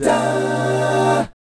1-CMI7  AA.wav